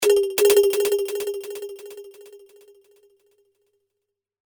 demo HEAR delay exemple
delay.mp3